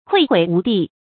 愧悔無地 注音： ㄎㄨㄟˋ ㄏㄨㄟˇ ㄨˊ ㄉㄧˋ 讀音讀法： 意思解釋： 指羞慚悔恨得無地自容 出處典故： 清 文康《兒女英雄傳》第八回：「姑娘，你問到這里，我安驥誠惶誠恐， 愧悔無地 。」